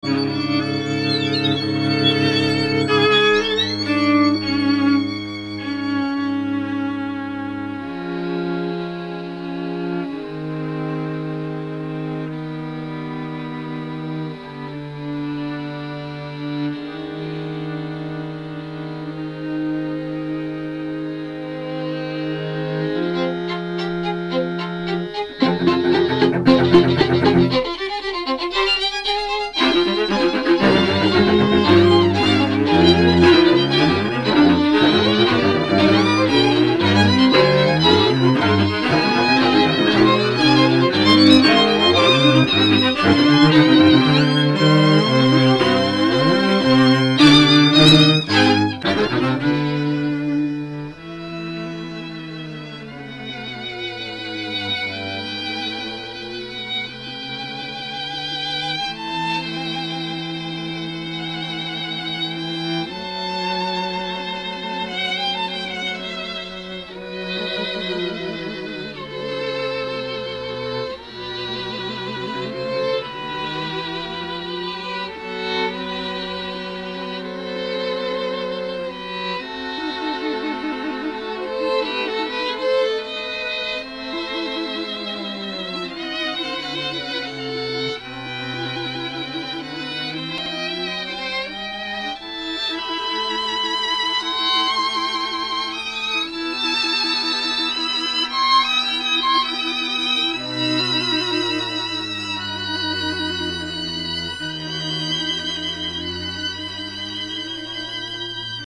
Vif